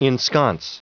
Prononciation du mot ensconce en anglais (fichier audio)
Prononciation du mot : ensconce